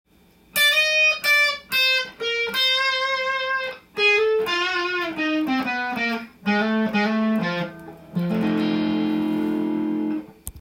フロントピックアップマイクの上空または
少しマイルドな音になっていきます。
丸い音にしたい時にこの位置で弾くことがあります。